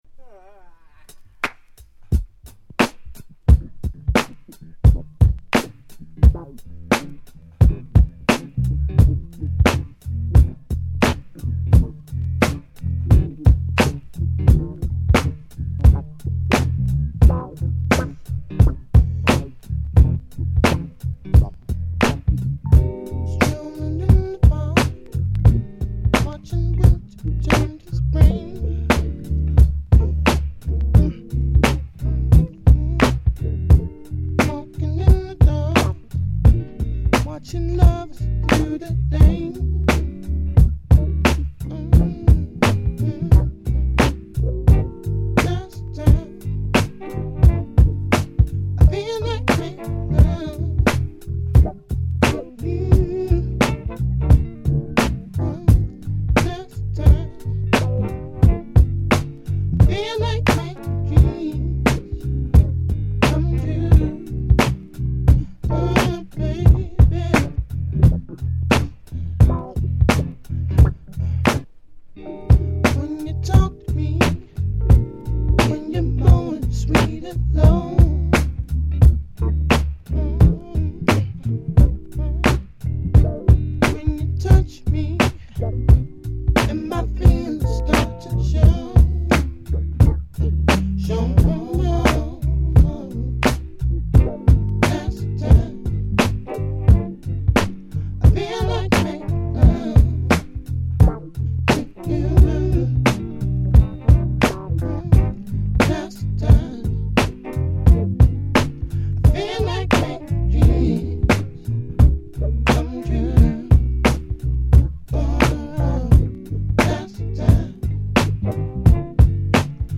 Neo Soul